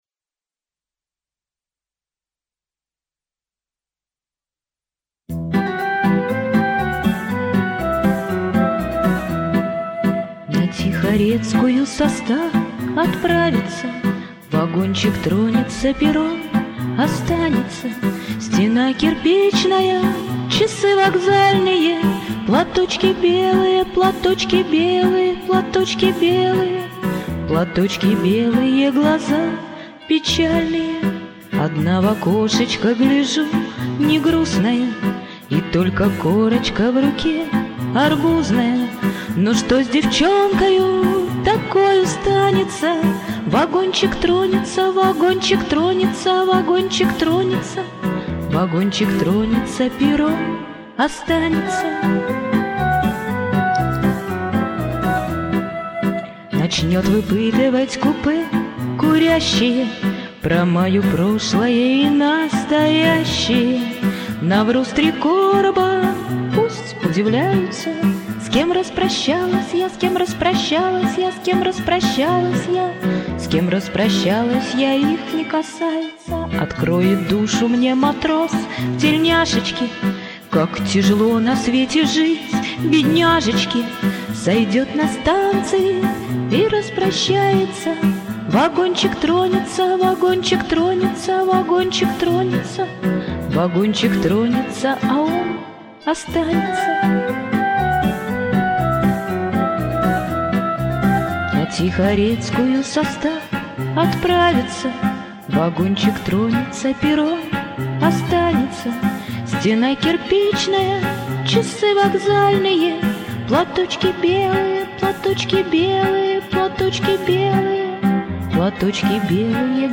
фонограммы караоке.